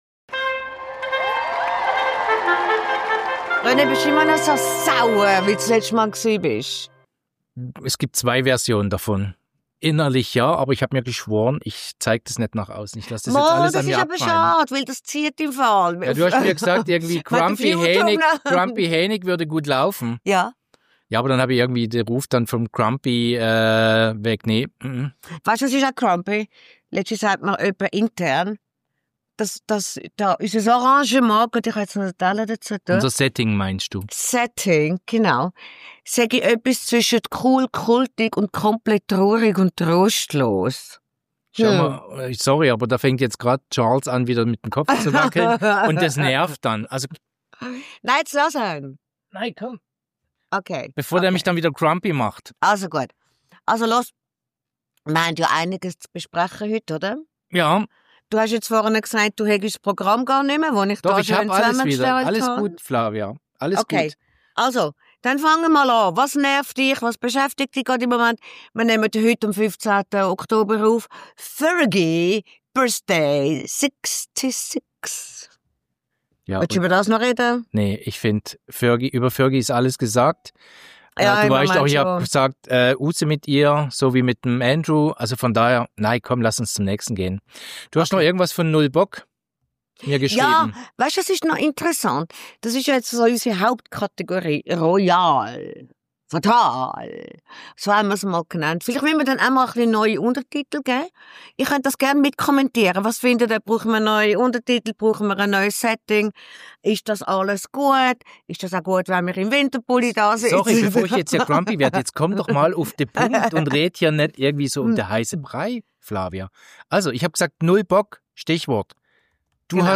Unser «RoyalTea»-Expertenduo weiss, warum und wohin die Kinder des künftigen Königs abgehauen sind. Zudem: Ein absurdes Schwangerschaftsgerücht um eine Teenie-Prinzessin.